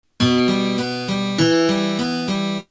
Способы аккомпанимента перебором
Em (2/4)